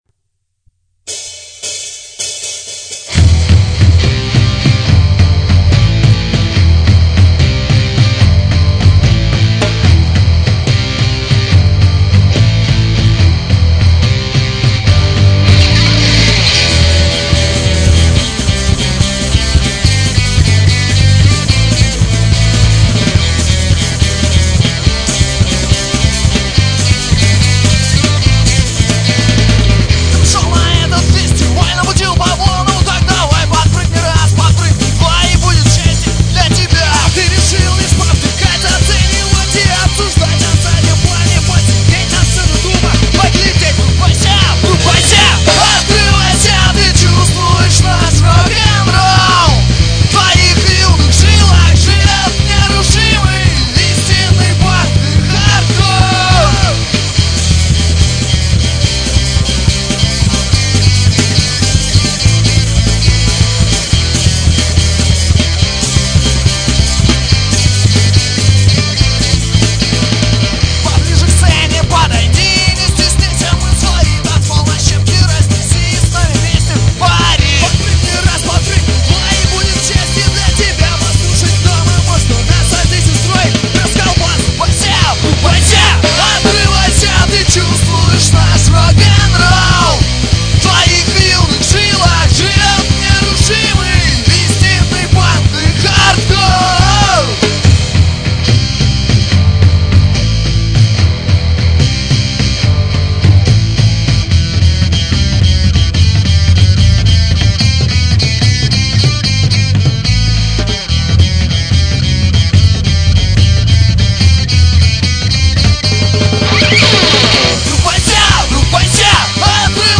Качество оставляет желать лучшего.
Панк, как панк Smile
По поводу вокала, это да) Я того же мнения) Но ко всему писались в "гараже" =)